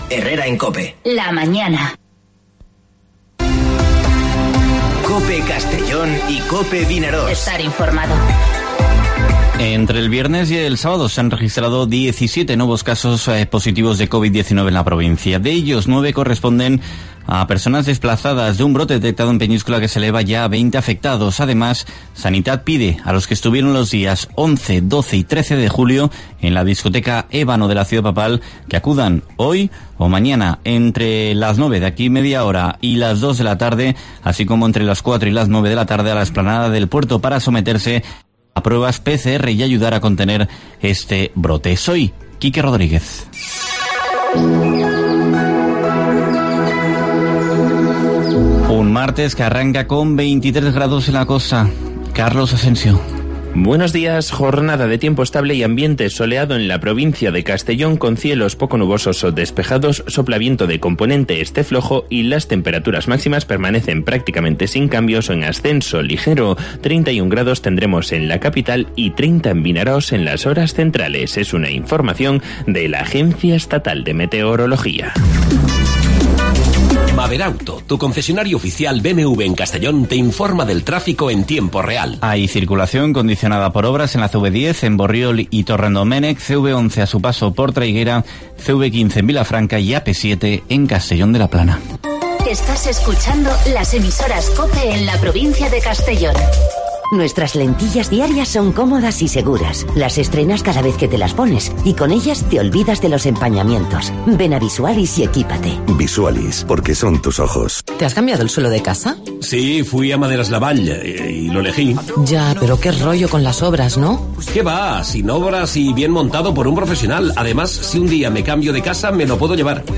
Informativo Herrera en COPE en la provincia de Castellón (21/07/2020)